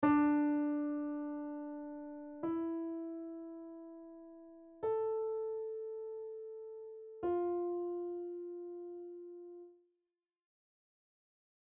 Look at the letters and find the notes on your piano; play the words
Piano Notes